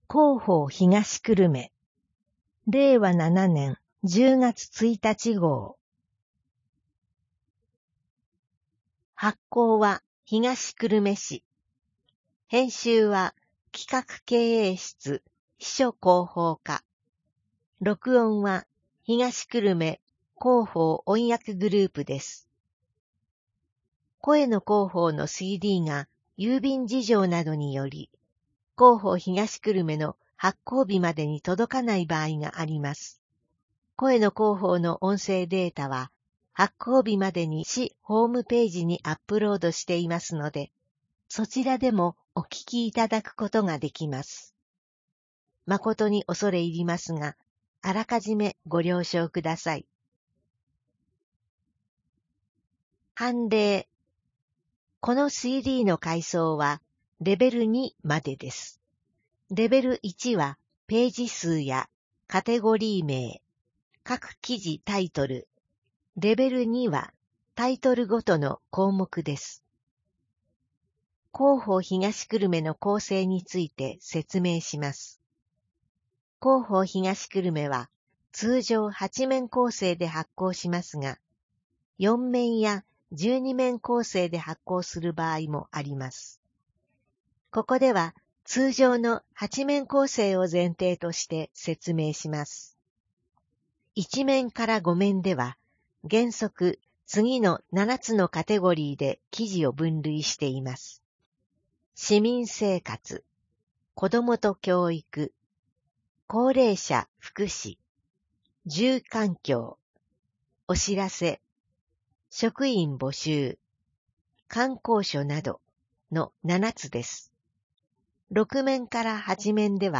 声の広報（令和7年10月1日号）